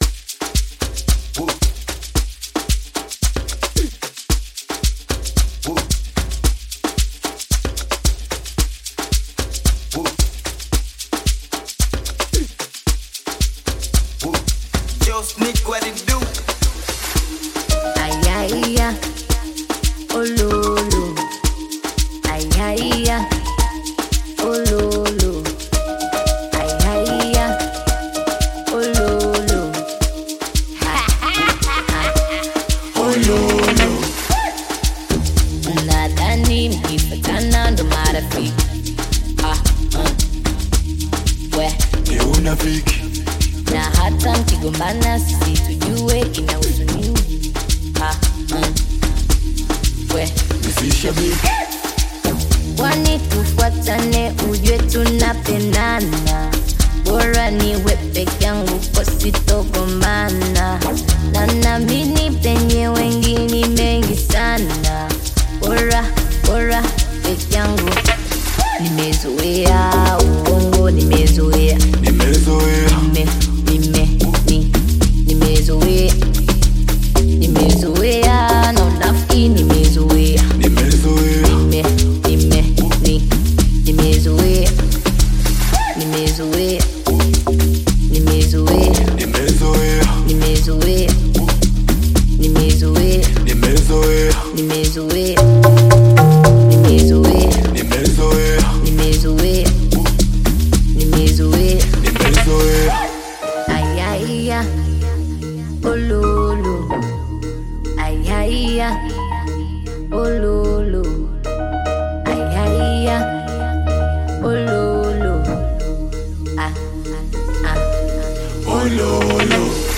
Upcoming Tanzanian bongo flava artist
Amapiano banger
African Music